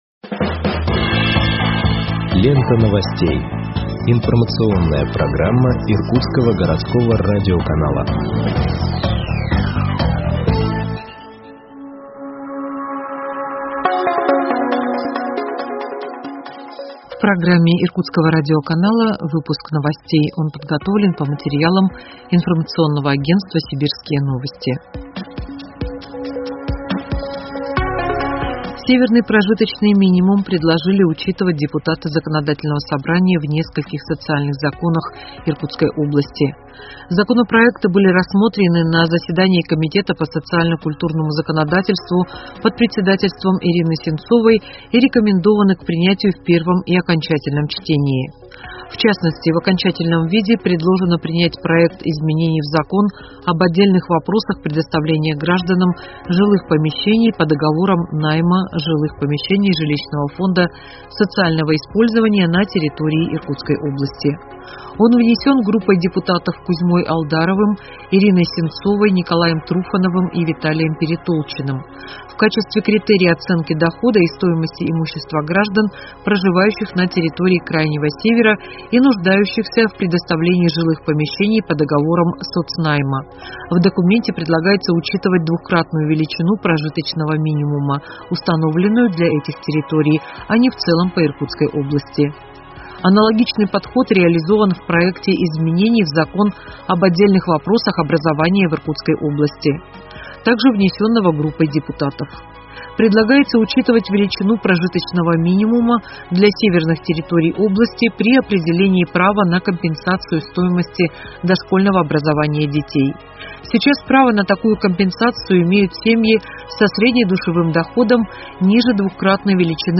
Выпуск новостей в подкастах газеты Иркутск от 20.09.2021 № 1